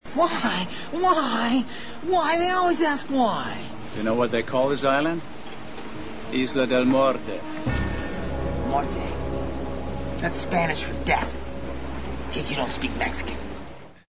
haunting chimes when the name is spoken.